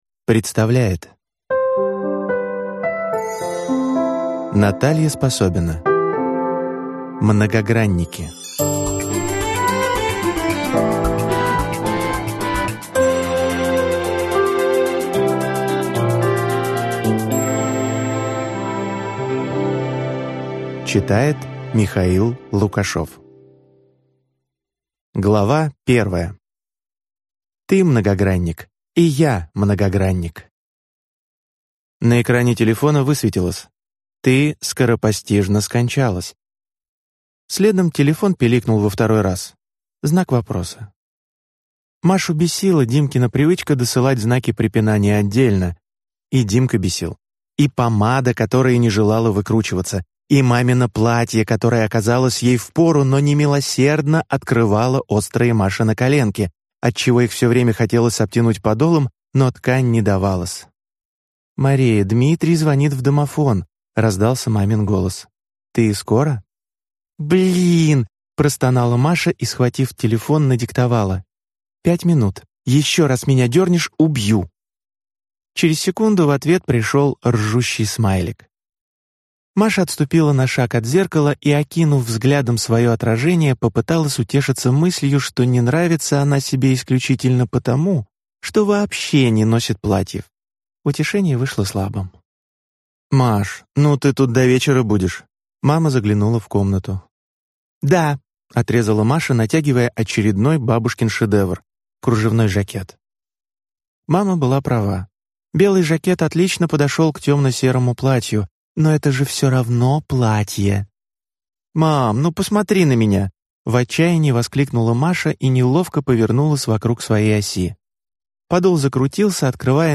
Аудиокнига Многогранники | Библиотека аудиокниг